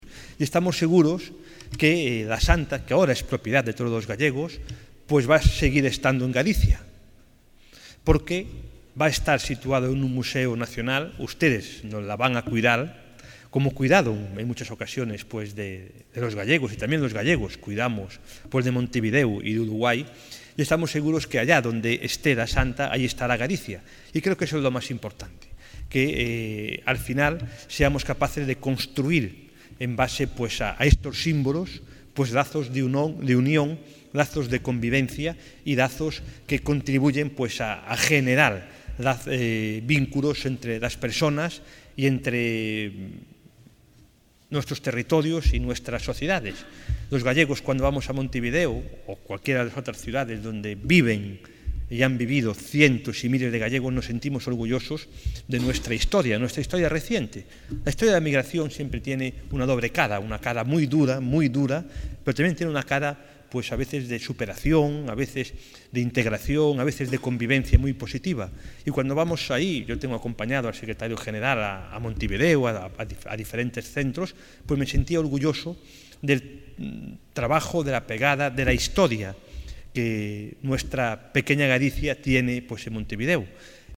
Declaraciones del conselleiro de Cultura, Educación, FP e Universidades, Román Rodríguez